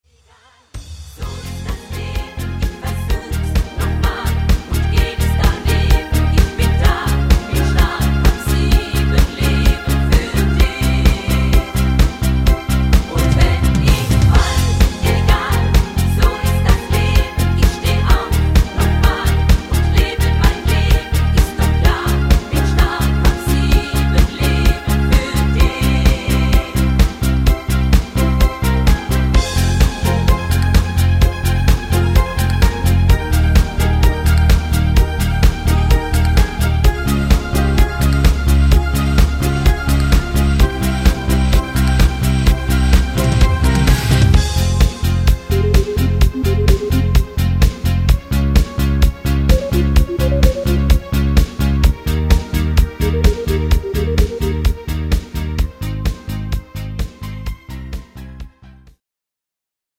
Super für die Sängerin